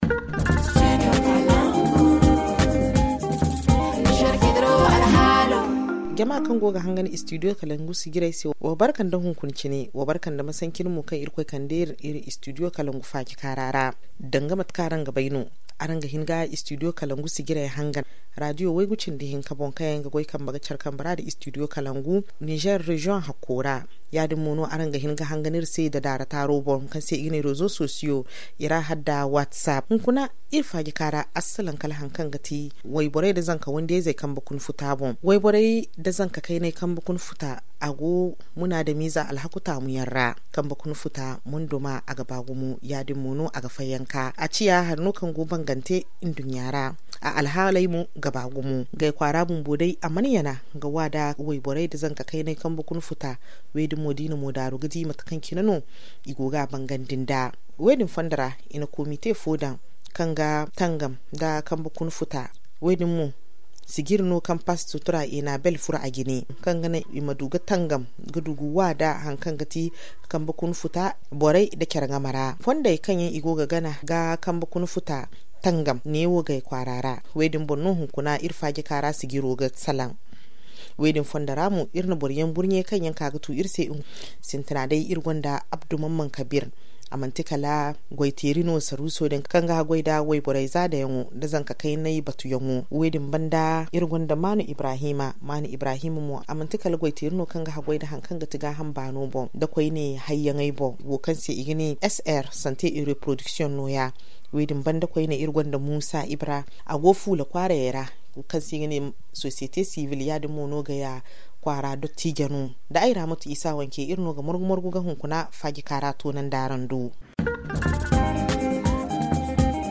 ZA Le forum en zarma Télécharger le forum ici.